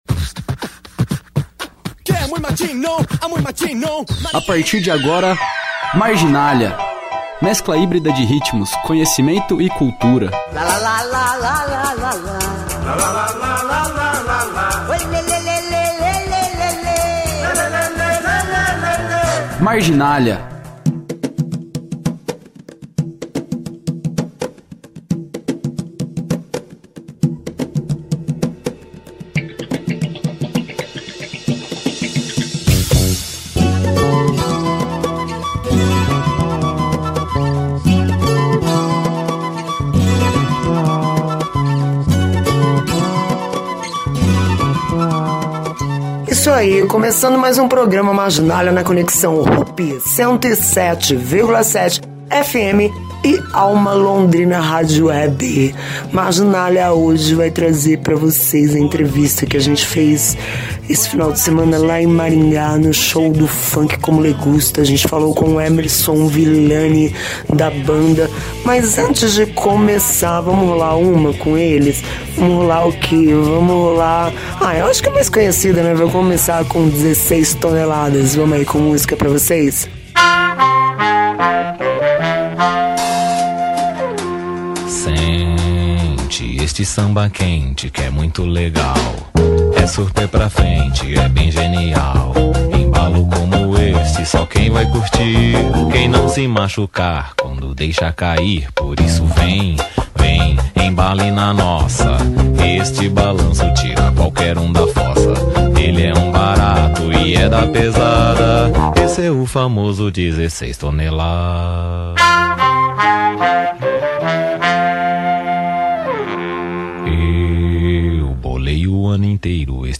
Entrevista Funk como me gusta.